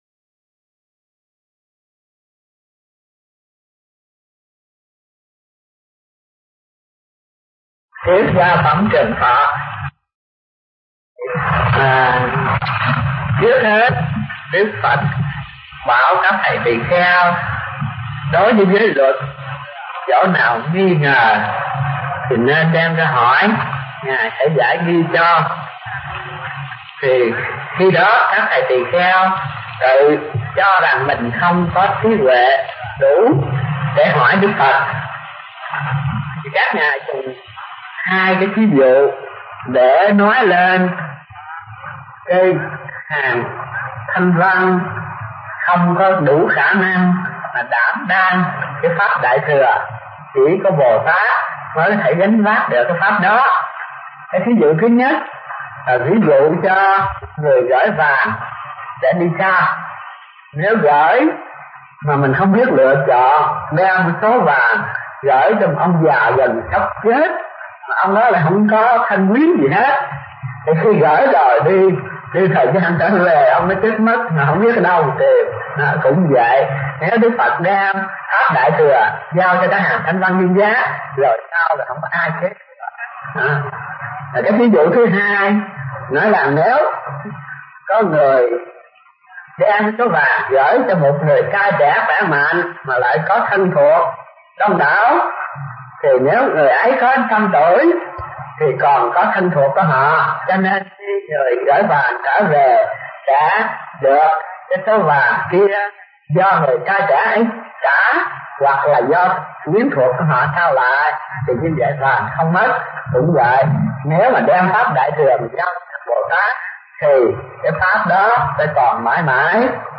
Mp3 Bài Giảng Kinh Đại Bát Niết Bàn 04 – Trường Thọ – Hòa Thượng Thích Thanh Từ